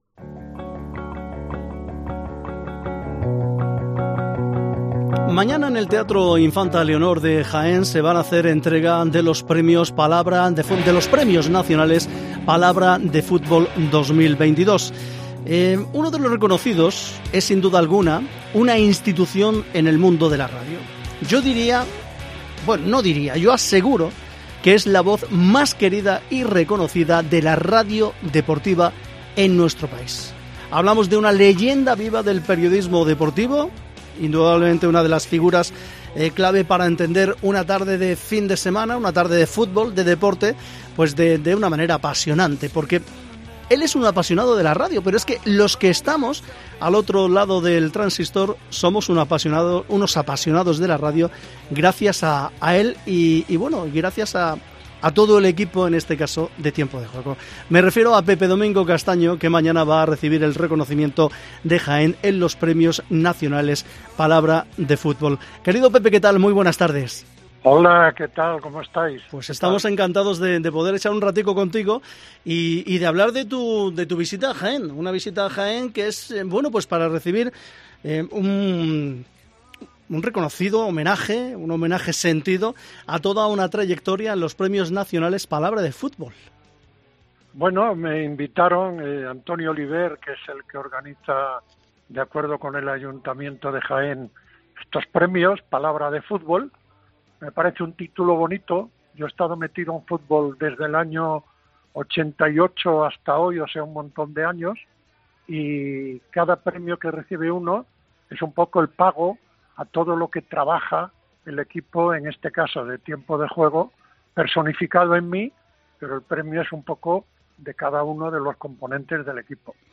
00:00 Volumen Descargar Charlamos con la voz más querida y reconocida de la radio deportiva española: Pepe Domingo Castaño Pepe Domingo Castaño será reconocido mañana en Jaén en los Premios Nacionales Palabra de Fútbol.